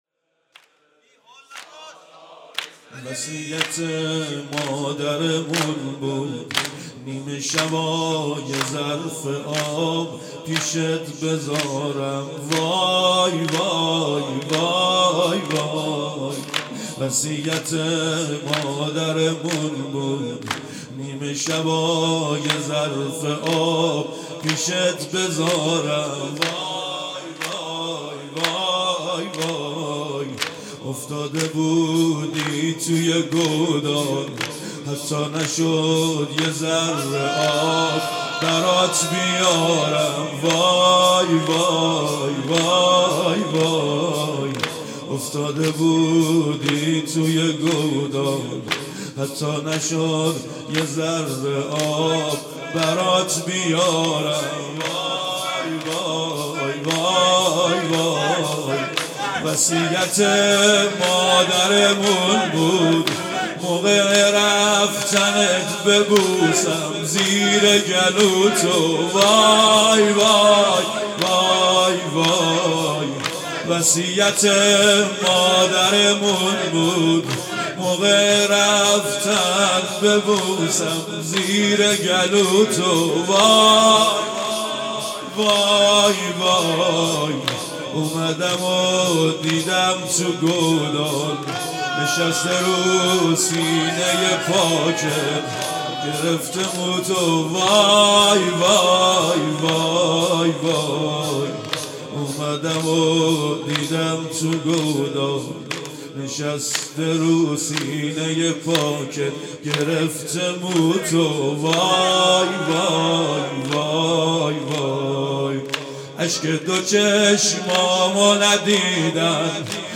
وصیت مادرمون بود|جلسه هفتگی ۴ آبان ۹۵
هیئت دانشجویی فاطمیون دانشگاه یزد